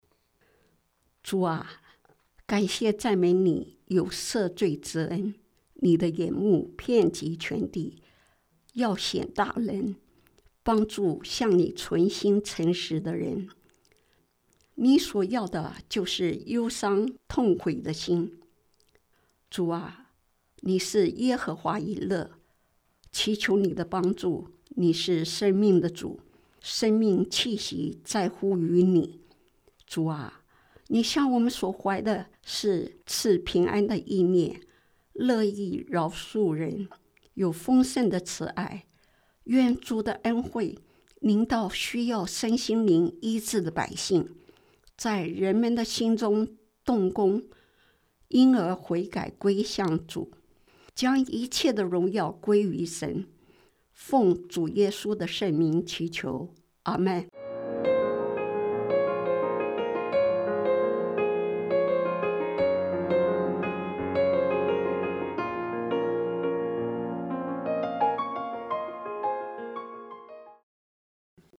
今日祈祷– 忧伤痛悔的心 – 生命之光广播电台